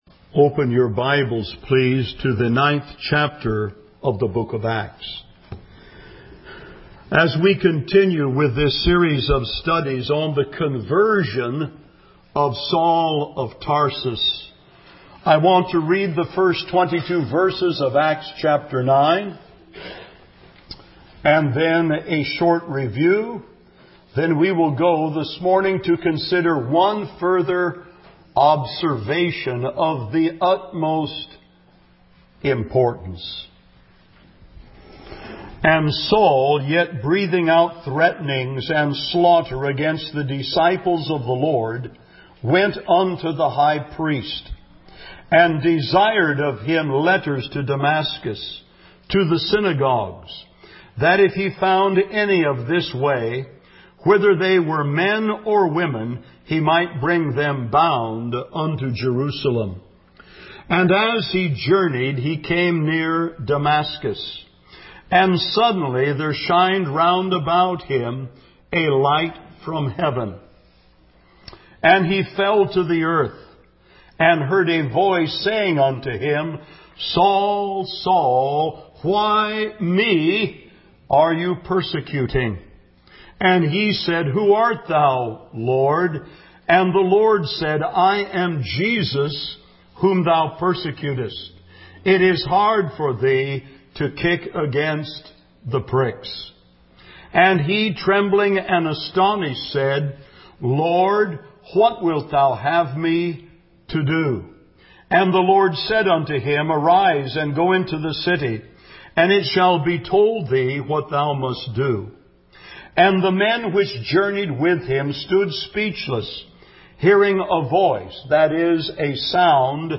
Sermons - Sovereign Grace Baptist Church of Silicon Valley